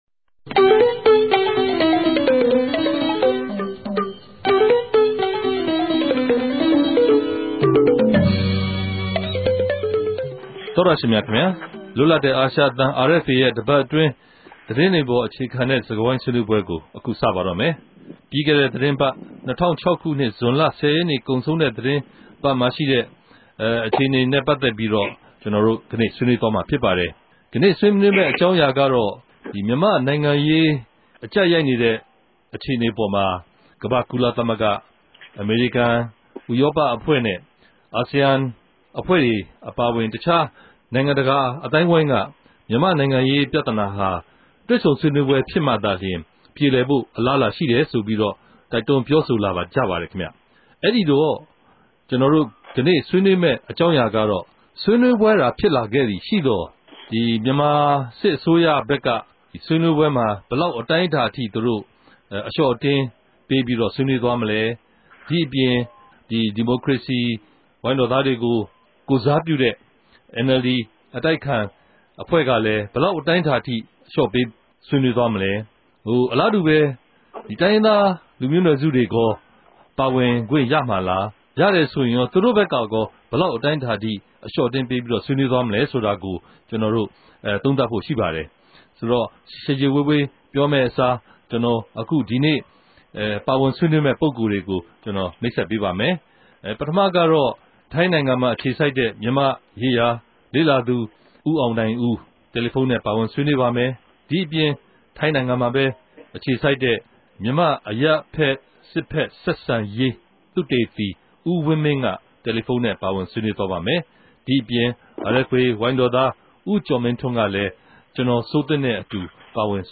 တယ်လီဖုန်းနဲႛ ပၝဝင်ဆြေးေိံြးထားုကပၝတယ်။